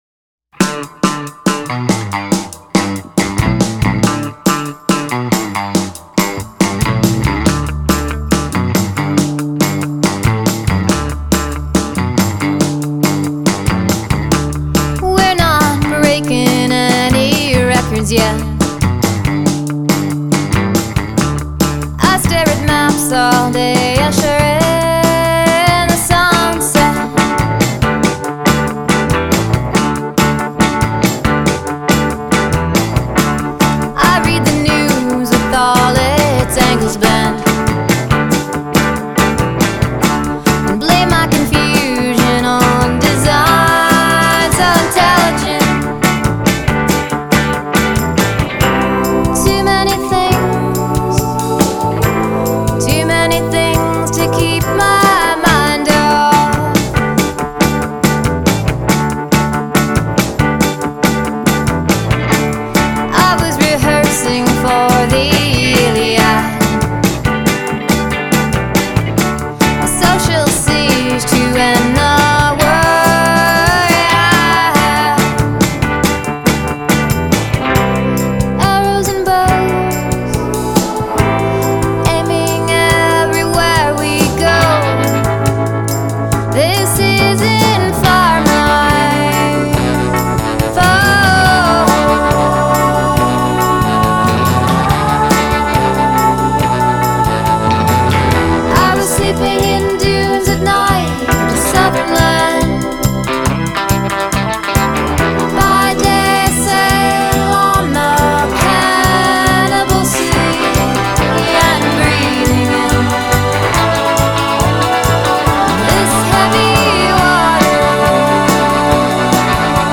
It’s a great smart pop song.